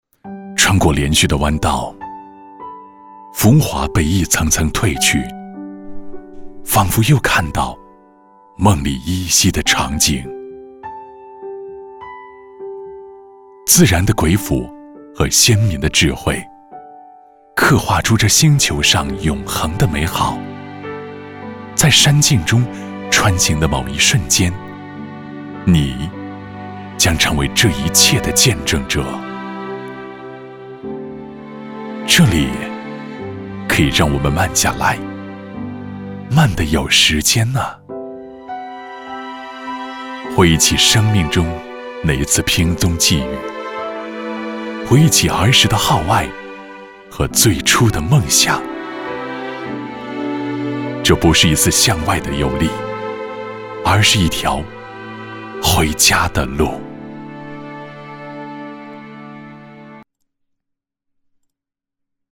微电影配音
男国129_其他_微电影_回家独白.mp3